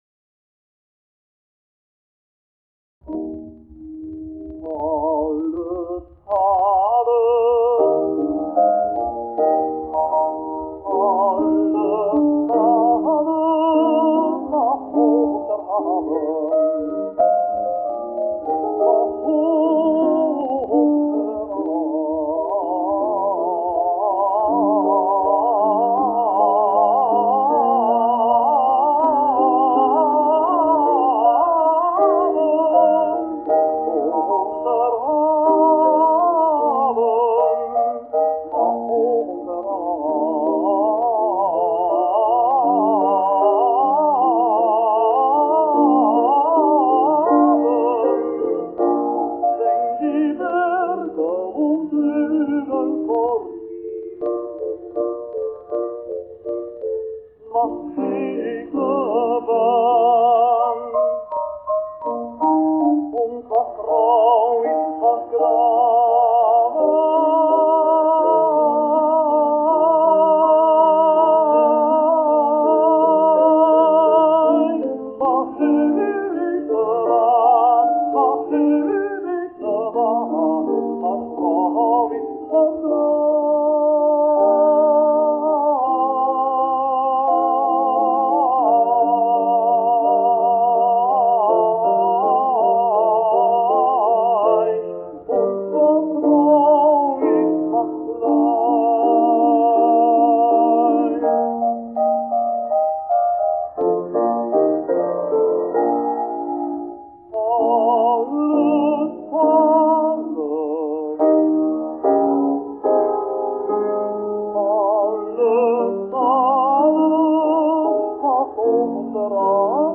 Several German opera theaters wanted to hire him, but he chose to be a concert tenor, specializing in oratorio. He sang 50 concerts per year, mostly but not exclusively in the Netherlands, and regularly with the Concertgebouw Orchestra.